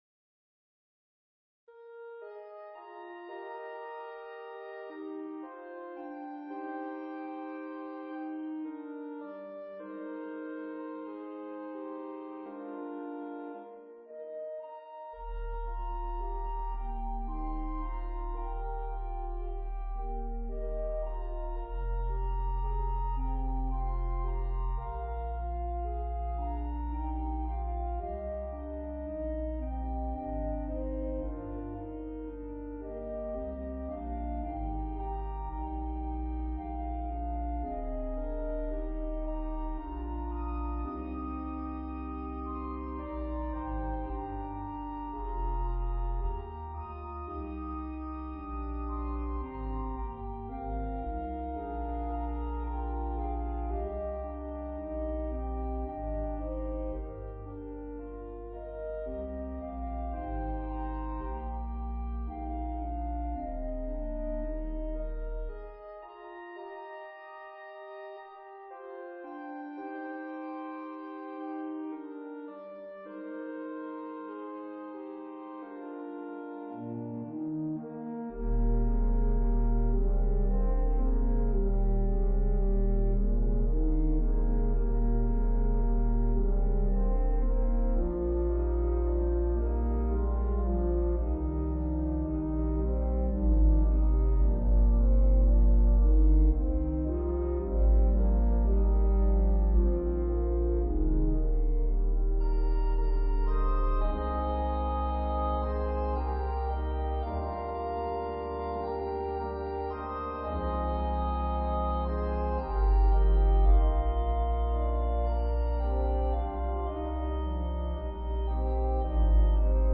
Voicing/Instrumentation: Organ/Organ Accompaniment We also have other 16 arrangements of " Savior, Redeemer of My Soul ".